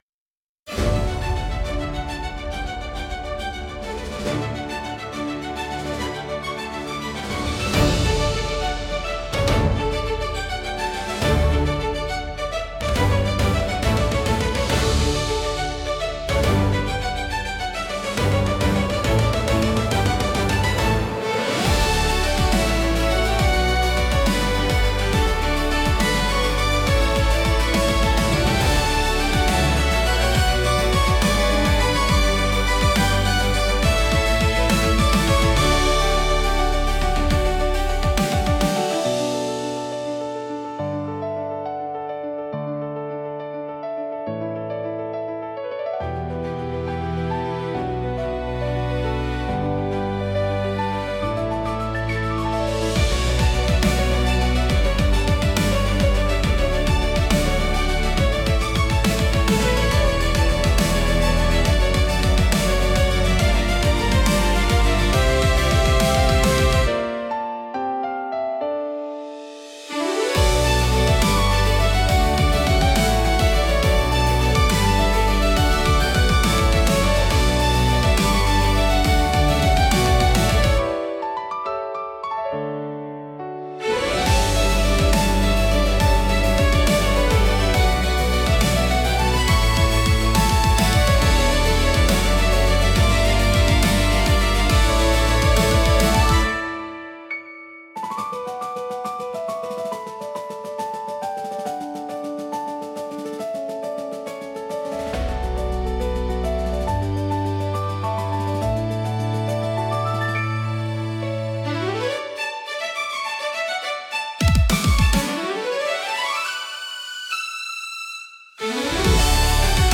優雅なストリングスと力強いブラスが重なり合い、広がるコーラスが新たな始まりの高揚感と希望をドラマチックに表現します。